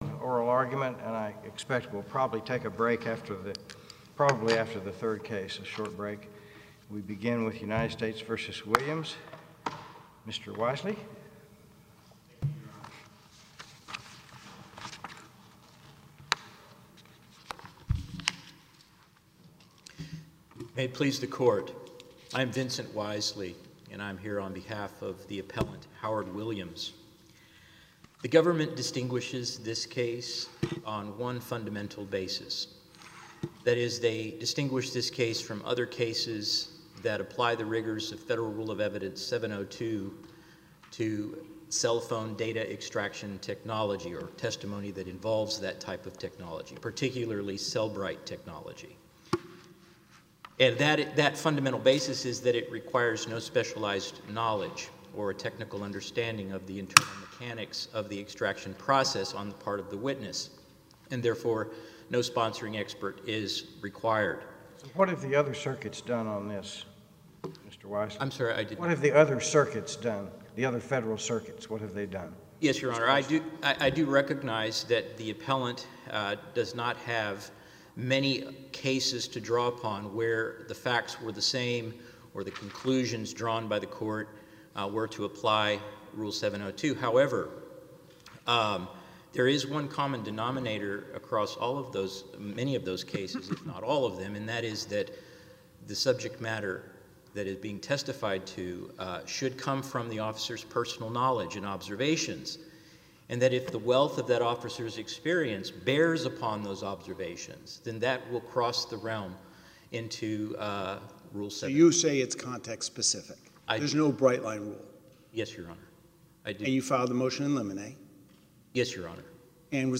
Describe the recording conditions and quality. Oral Argument - U.S. Court of Appeals for the Fifth Circuit Oral Argument - U.S. Court of Appeals for the .m4a